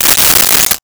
Silverware Movement 04
Silverware Movement 04.wav